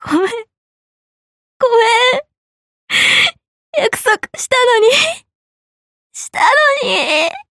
Lilith has a death voice line that only triggers when your bond is 5 or higher
where she cries and apologizes for not having kept her promise to you (In Bond 5 she says that she will kill herself when you die so that you don't die alone)
quite rare to have a voice line where the servant literally cries